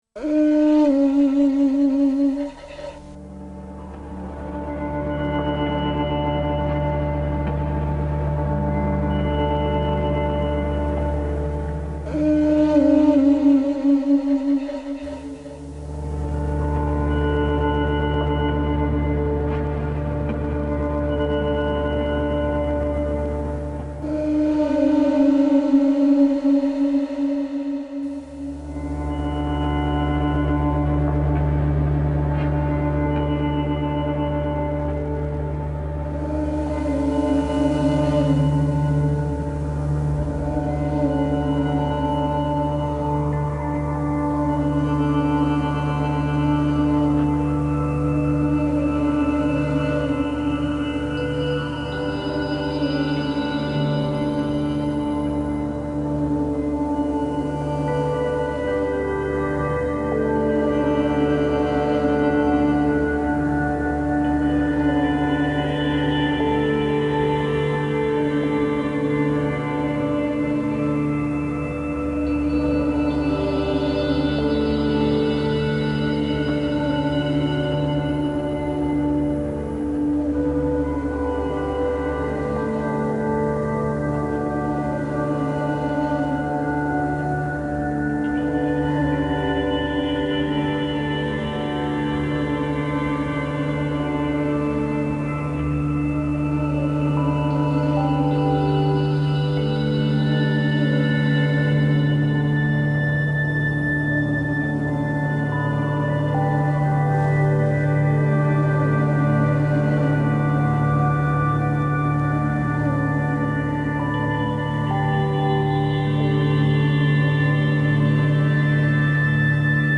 Duet for conch shell and synthesisers
A sound of beauty, then, but also of ceremonial significance - a treasure.